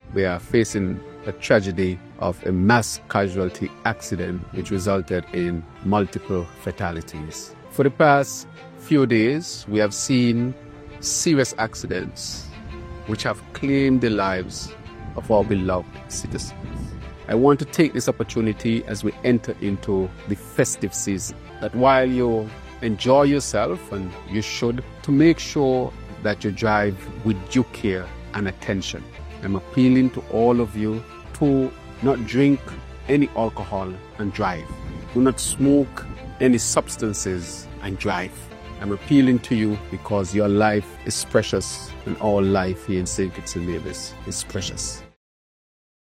Prime Minister and Minister of Health, Dr. Terrance Drew, issued a statement and said there were “multiple fatalities”.